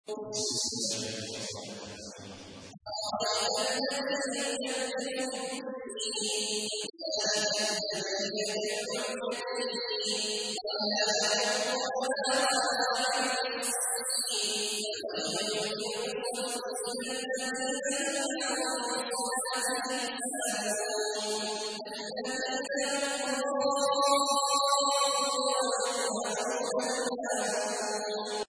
تحميل : 107. سورة الماعون / القارئ عبد الله عواد الجهني / القرآن الكريم / موقع يا حسين